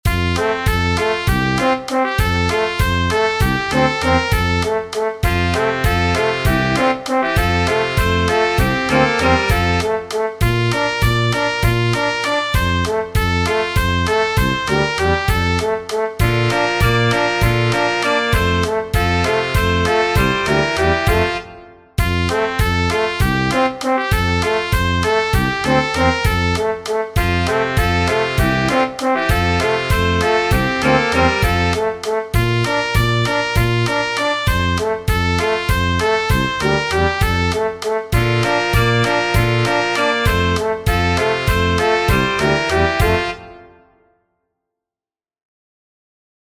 Tradizionale Genere: Folk |: Schneida, Schneida, singts oans, gehts Weita,weita, singts a scheens Liad.
:| Folk tradizionale della Baviera (Germania) Schneider Zwiefacher Schneider Zwiefacher letto 10 volte